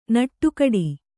♪ naṭṭu kaḍi